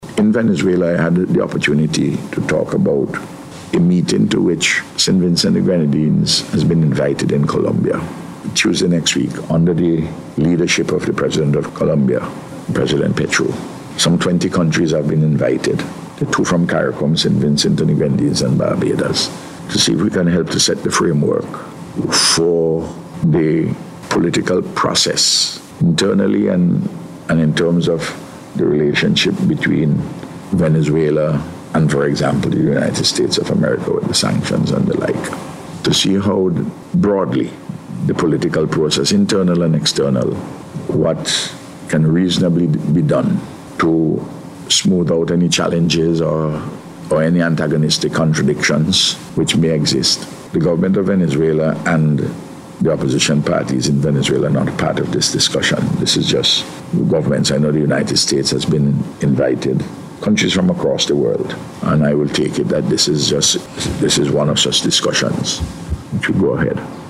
Dr. Gonsalves made the disclosure while addressing a press conference at Cabinet Room yesterday.